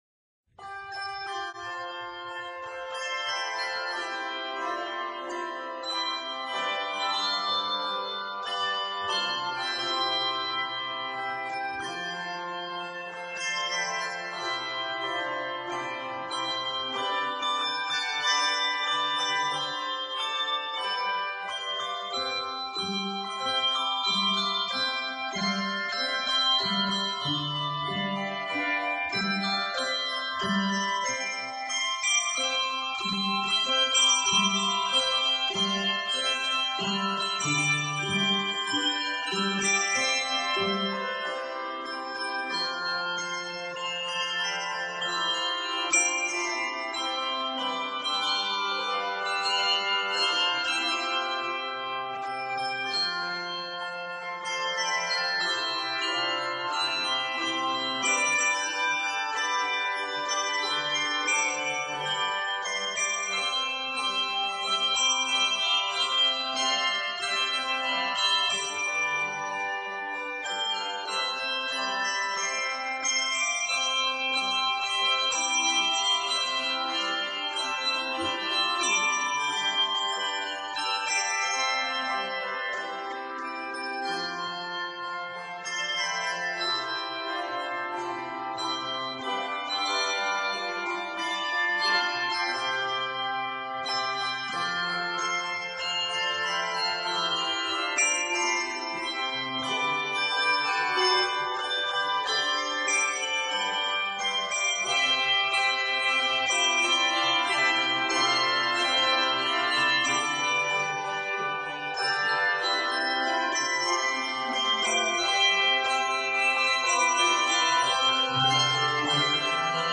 This old gospel hymn